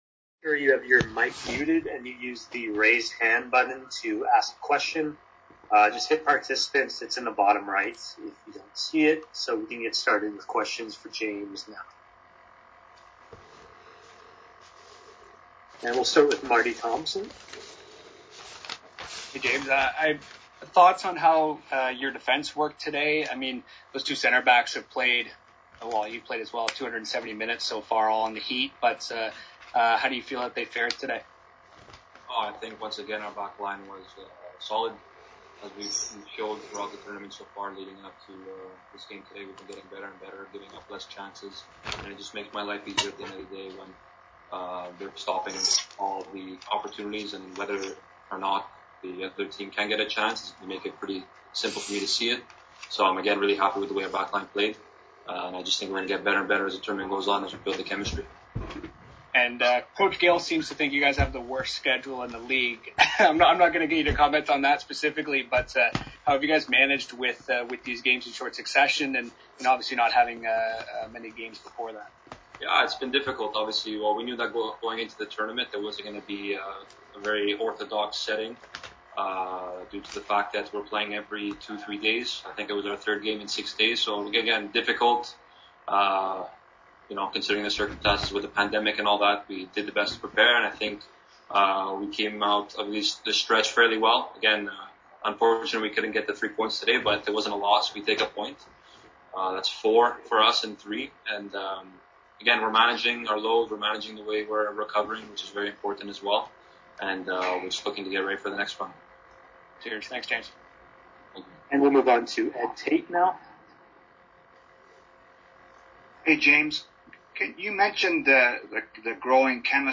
BOX SCORE Post game press conference conducted via Zoom.